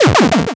hit_hurt2.wav